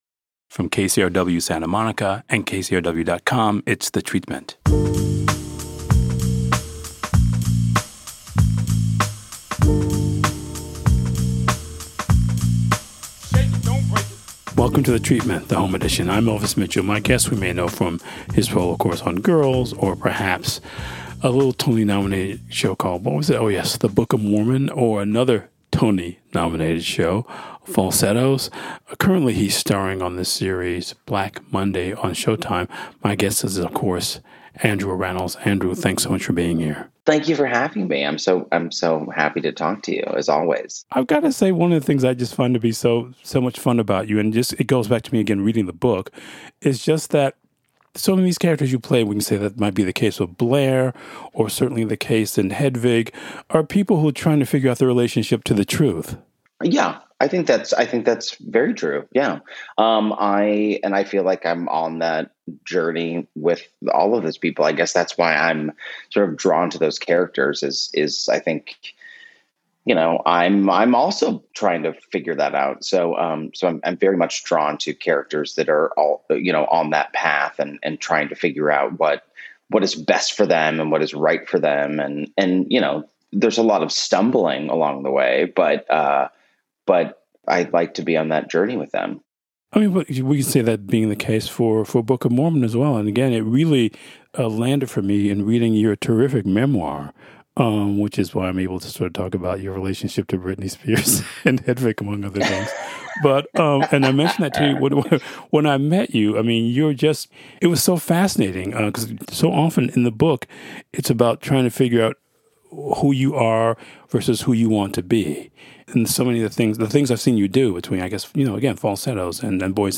This week on The Treatment, Elvis welcomes actor, writer and director Andrew Rannells.
The following interview has been abbreviated and edited for clarity.